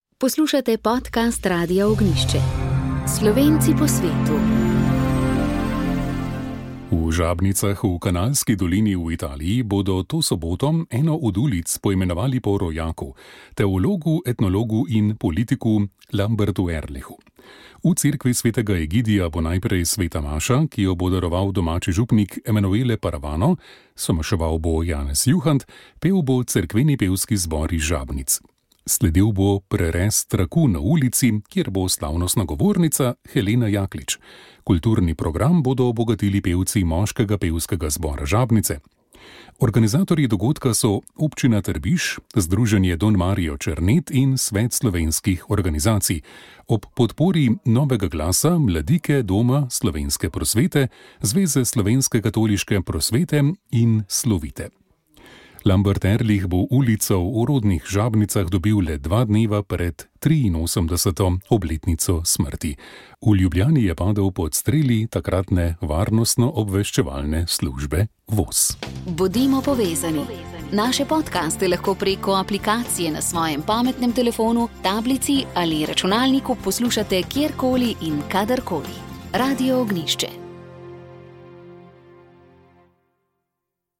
Sv. maša iz cerkve Marijinega oznanjenja na Tromostovju v Ljubljani 16. 10.